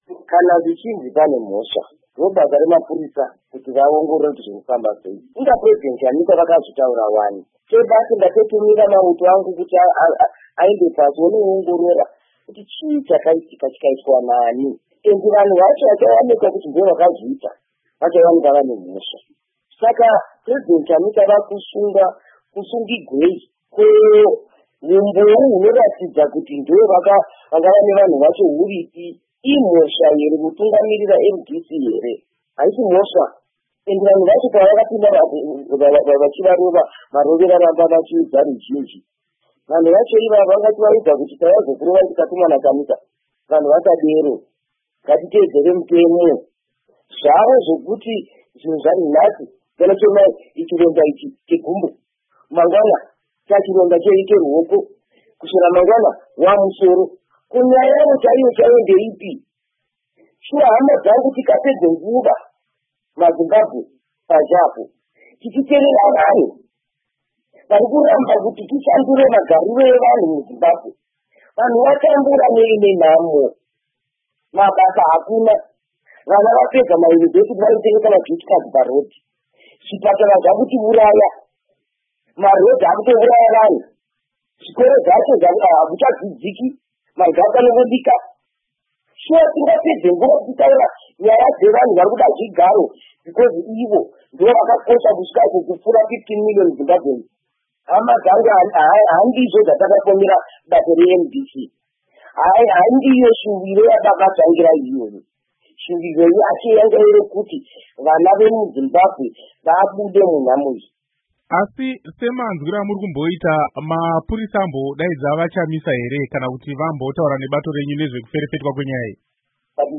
Hurukuro naMuzvare Thabitha Khumalo